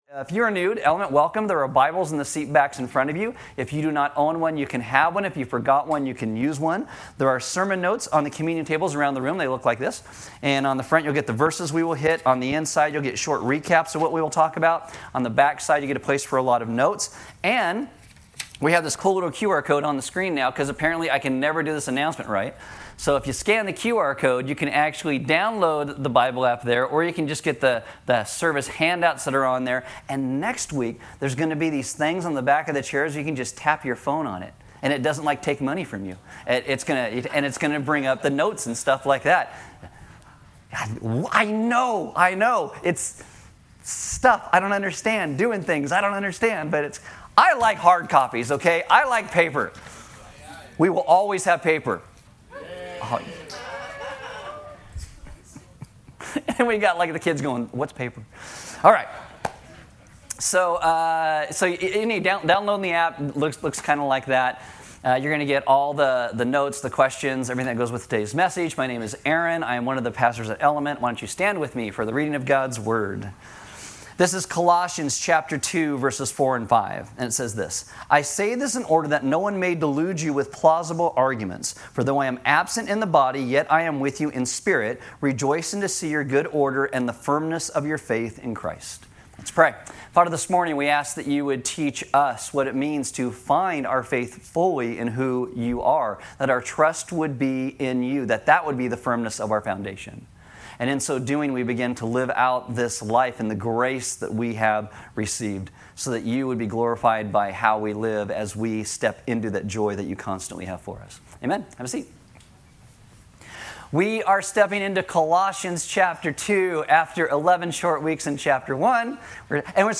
Service Audio Message The Gospel is the announcement of what Jesus did to save and restore us through His birth, life, death, and resurrection.